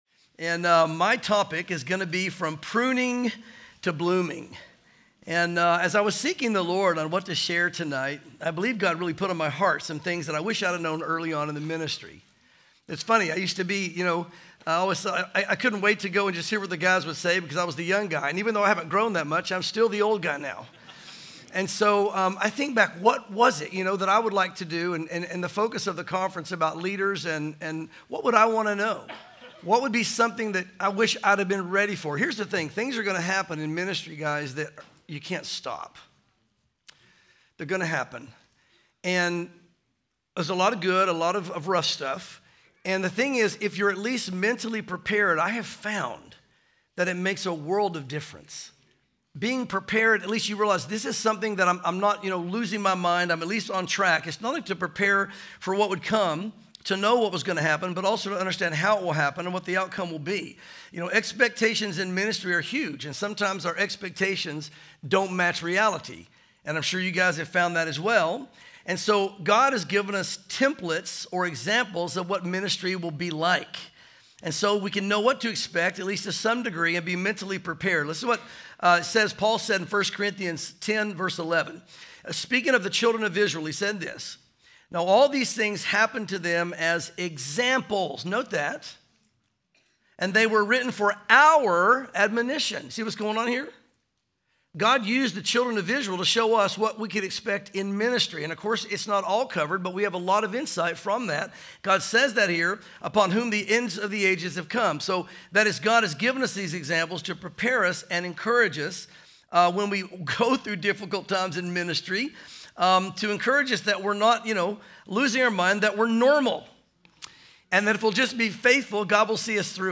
Home » Sermons » From Pruning to Blooming
2025 DSPC Conference: Pastors & Leaders